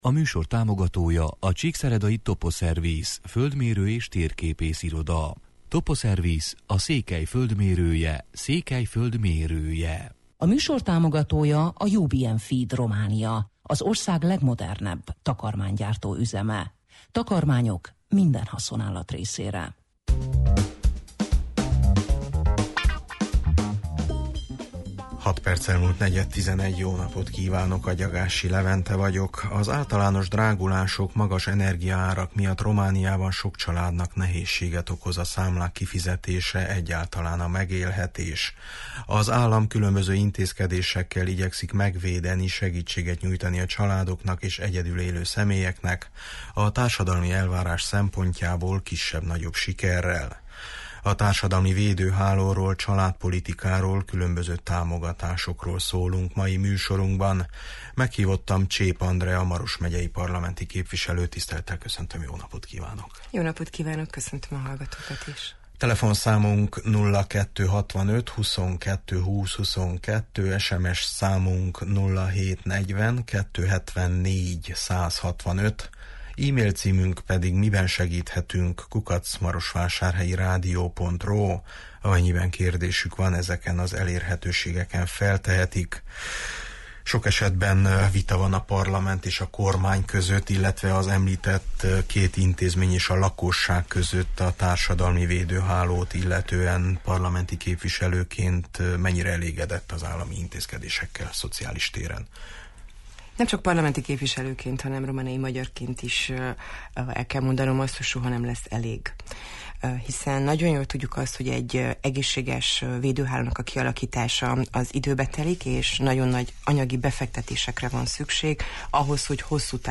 Meghívottam Csép Andrea Maros megyei parlamenti képviselő: